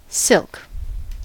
silk: Wikimedia Commons US English Pronunciations
En-us-silk.WAV